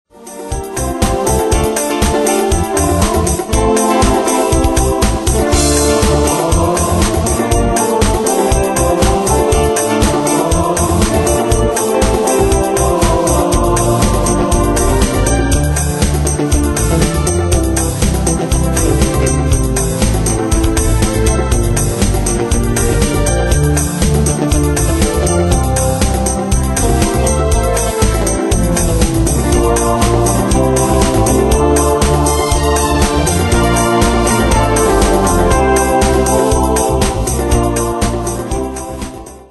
Demos Midi Audio
Style: Dance Année/Year: 1992 Tempo: 120 Durée/Time: 3.38
Pro Backing Tracks